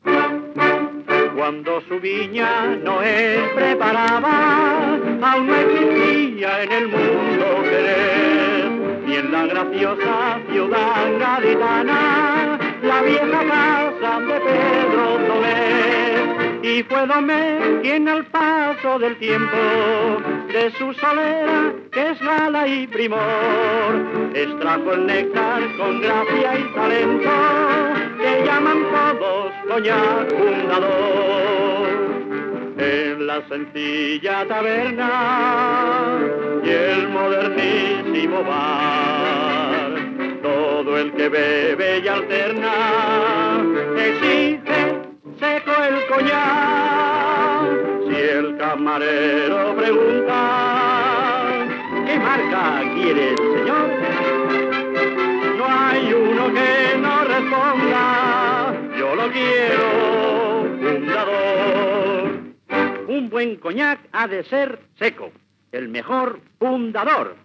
Cançó publicitària